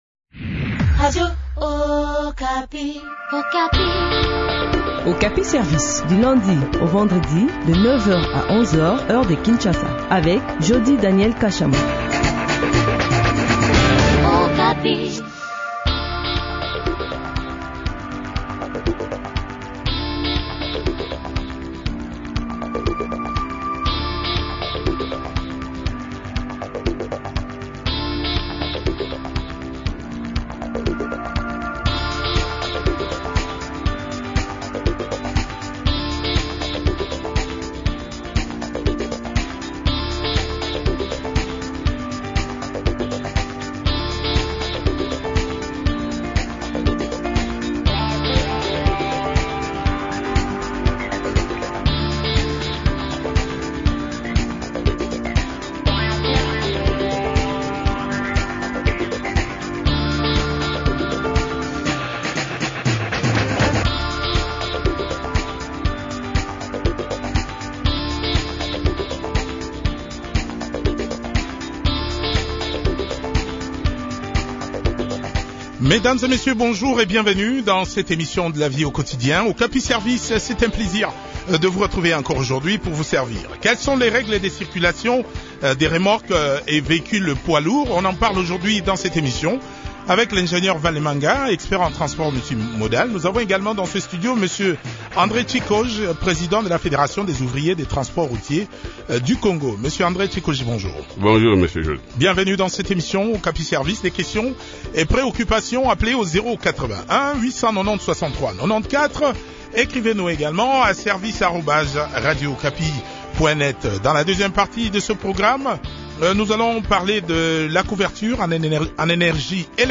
expert en transport multimodal.